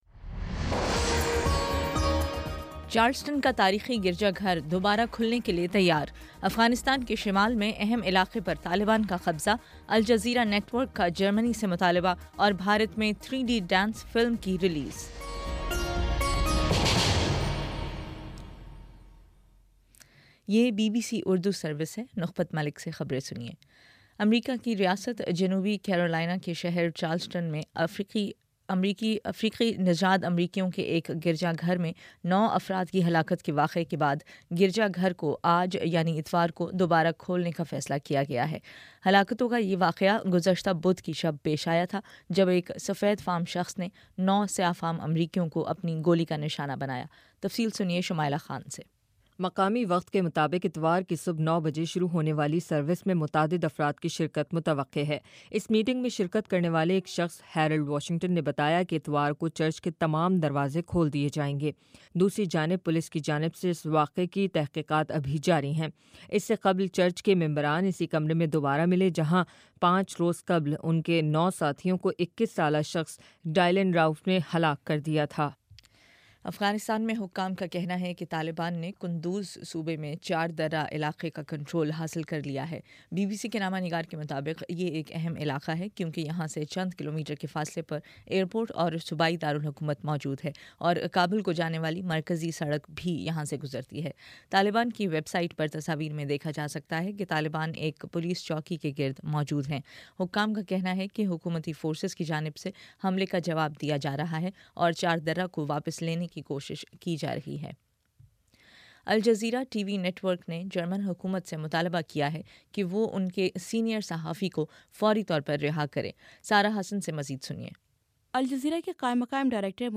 جون 21: شام پانچ بجے کا نیوز بُلیٹن